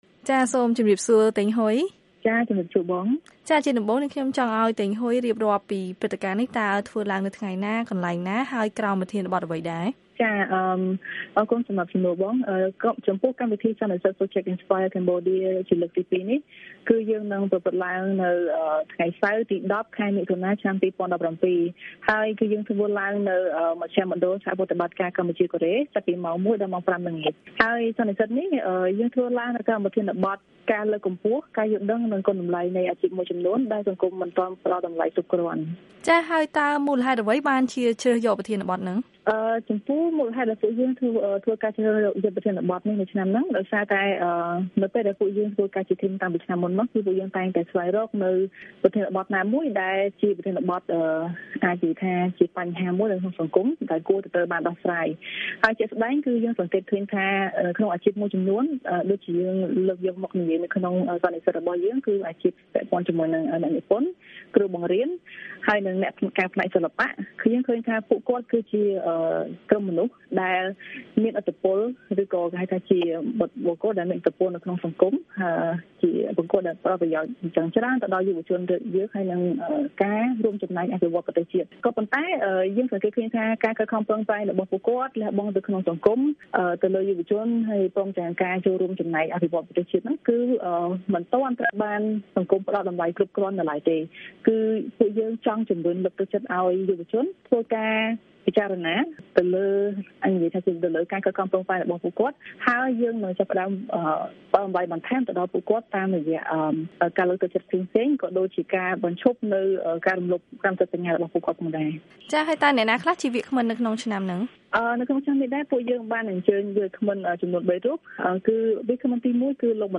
បទសម្ភាសន៍៖ ការលើកកម្ពស់អាជីពដែលសង្គមមិនបានផ្តល់តម្លៃគ្រប់គ្រាន់